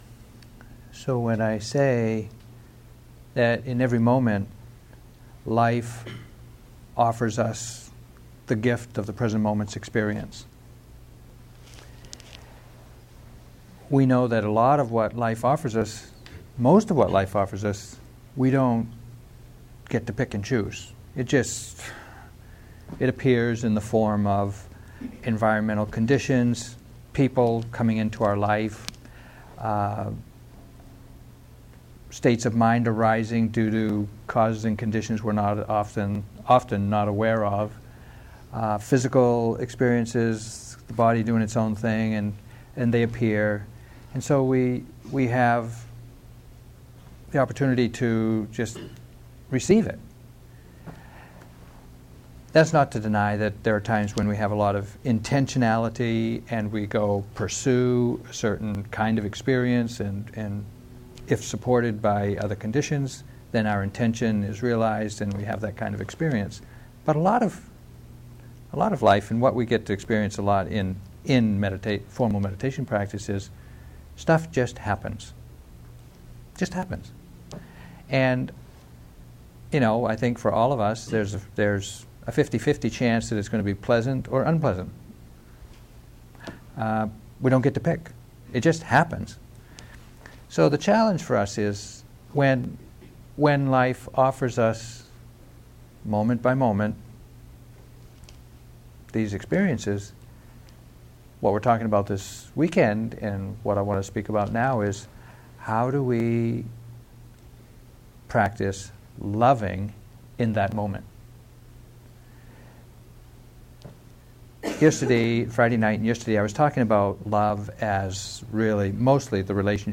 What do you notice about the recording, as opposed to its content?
2013-03-03 Venue: Seattle Insight Meditation Center Series